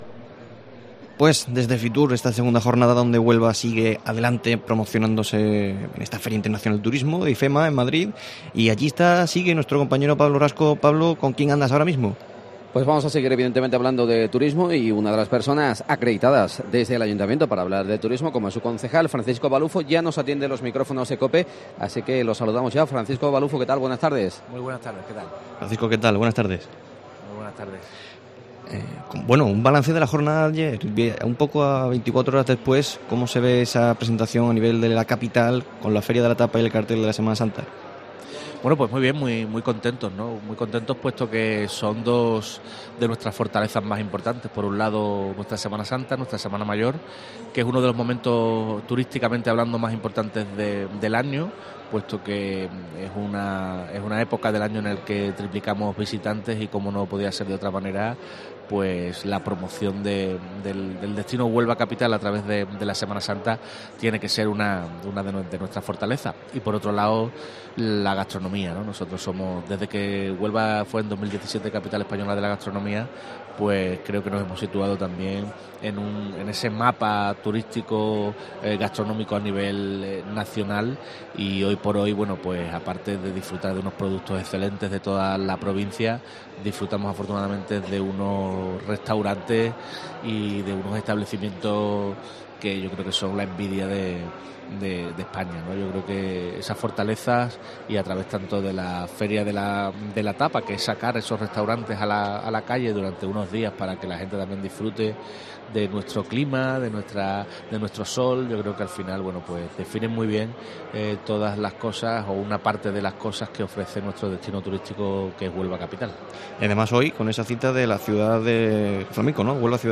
Francisco Baluffo, concejal de Turismo en el Ayuntamiento de Huelva, analiza en COPE Huelva la apuesta del consistorio en la Feria Internacional de Turismo